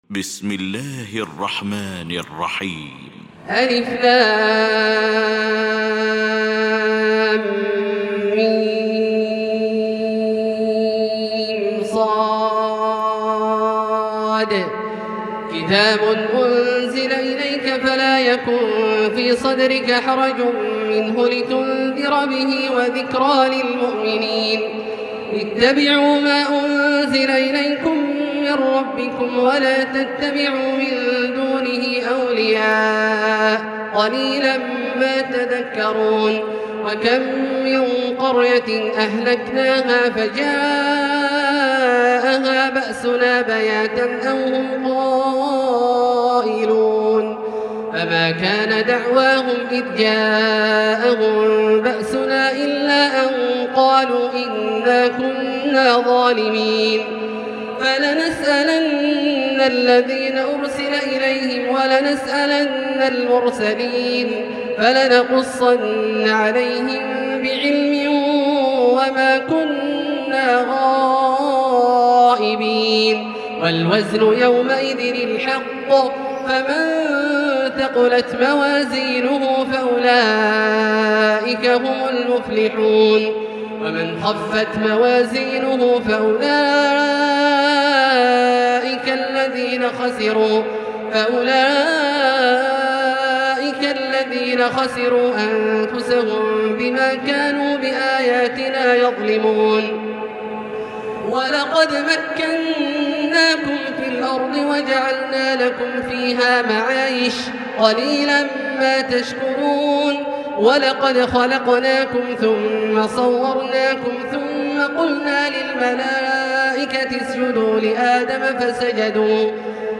المكان: المسجد الحرام الشيخ: معالي الشيخ أ.د. بندر بليلة معالي الشيخ أ.د. بندر بليلة فضيلة الشيخ عبدالله الجهني فضيلة الشيخ ماهر المعيقلي فضيلة الشيخ ياسر الدوسري الأعراف